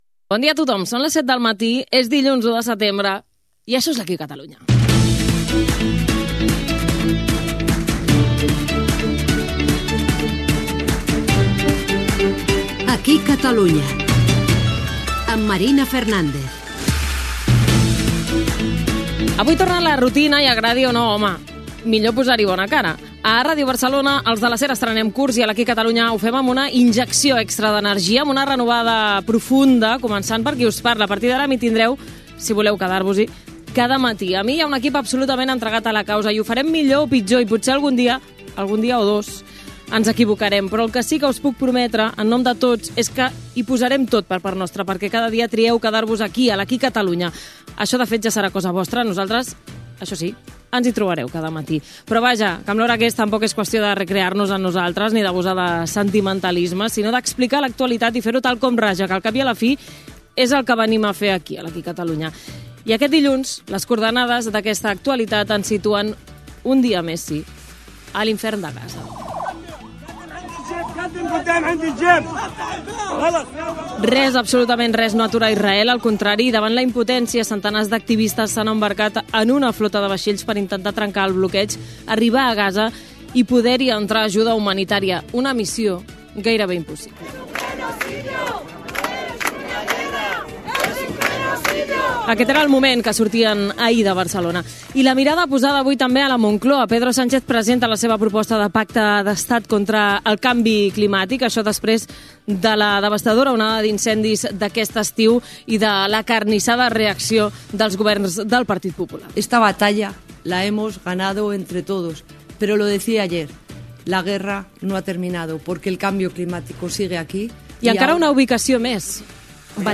Hora, careta, presentació del primer programa, en començar la temporada 2025-2026. Titulars: salpa cap a Gaza la Global Sumud Flotilla, pacte d'Estat contra el canvi climàtic, resultat del Futbol CLub Barcelona masculí.
Info-entreteniment